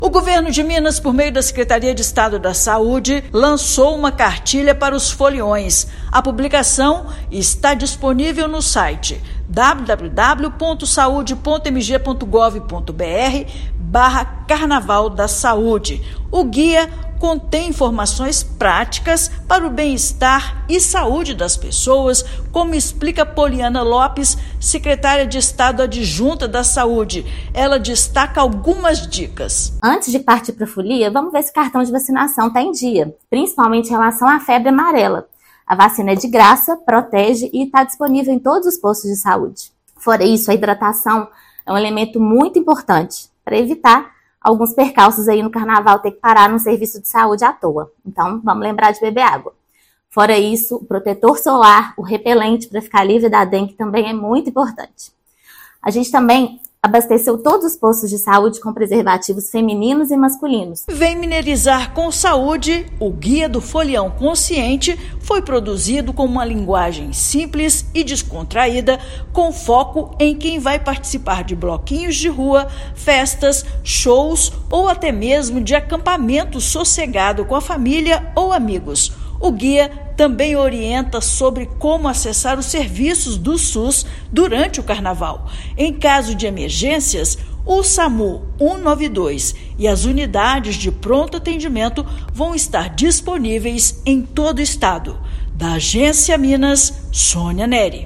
[RÁDIO] Governo de Minas lança guia para foliões curtirem o Carnaval sem descuidar da saúde
Cartilha Vem Mineirizar com Saúde oferece dicas para garantir o bem-estar durante os dias de festa no estado. Ouça matéria de rádio.